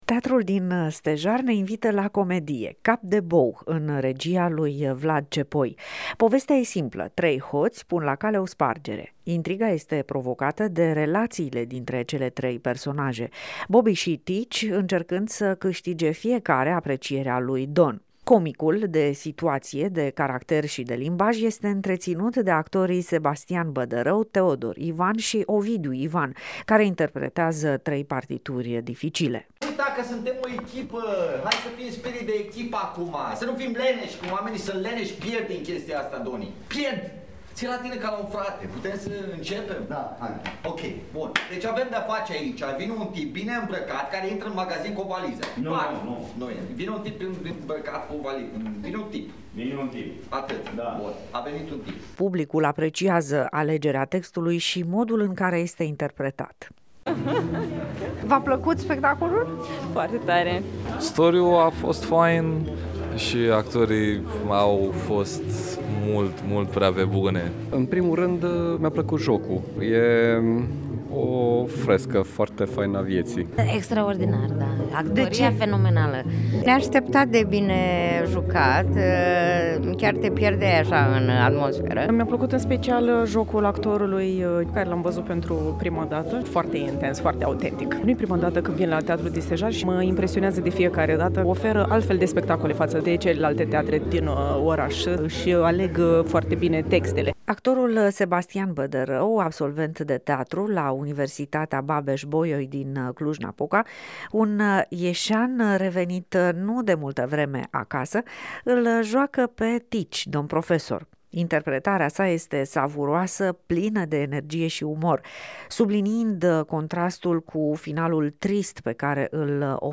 Reporter: E comedie, dar se termină în lacrimi.